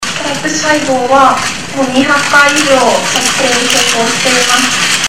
2014年4月9日、英科学誌『ネイチャー』に提出したSTAP細胞論文を巡り、理化学研究所の調査委員会が 改ざんやねつ造などの不正があったと判断を下した件に対して、小保方春子氏が記者会見において謝罪と不服申し立ての説明を行った。